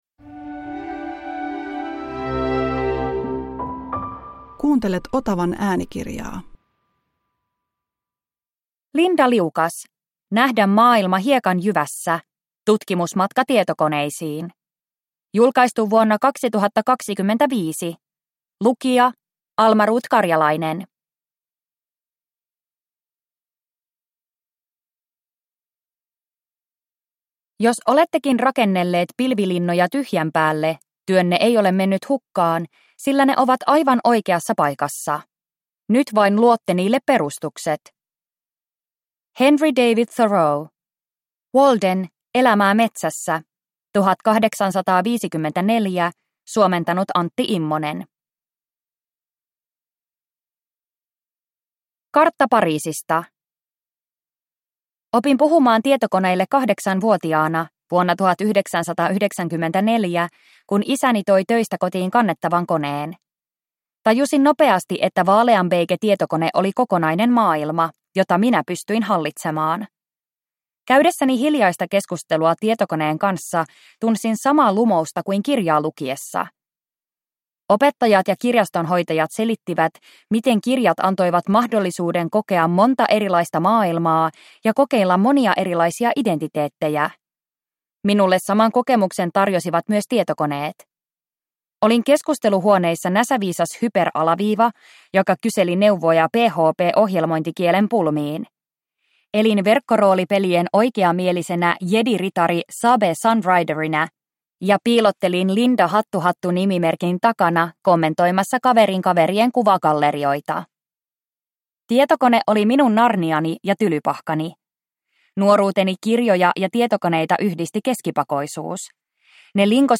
Nähdä maailma hiekanjyvässä – Ljudbok